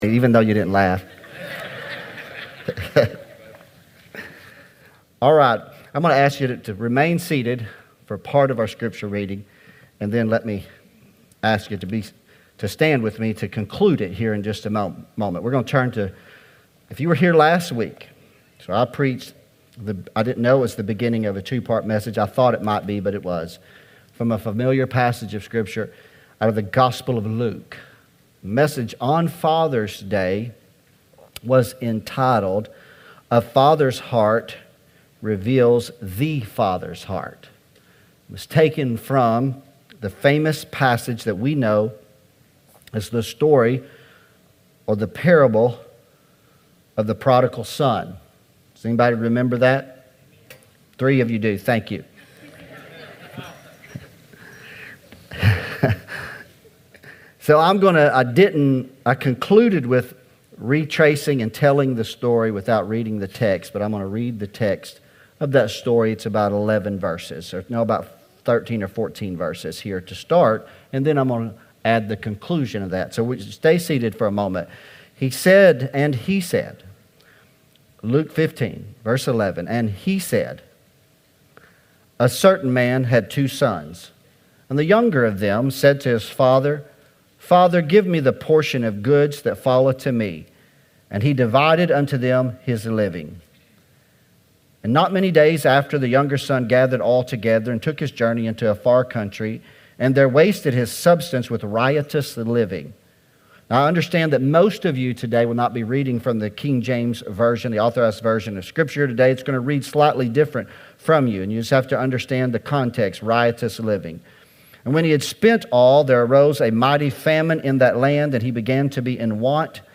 Sermons | The Assembly Heber Springs